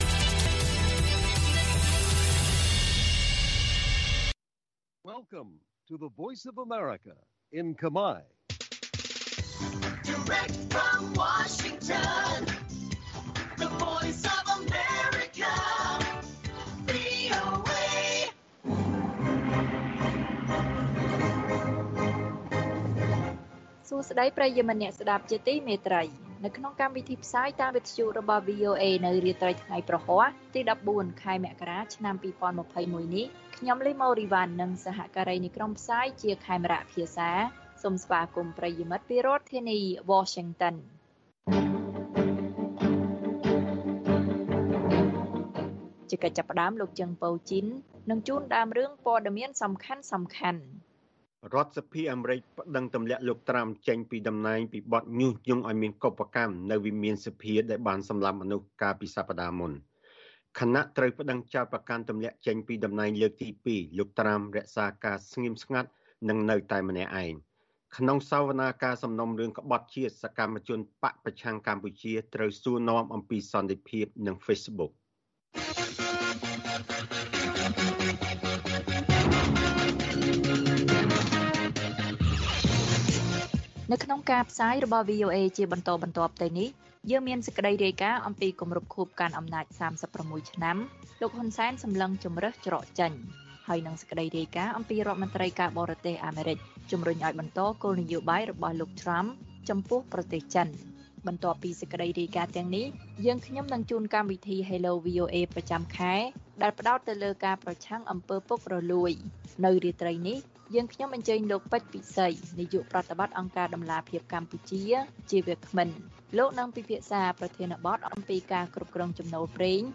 ព័ត៌មានពេលរាត្រី៖ ១៤ មករា ២០២១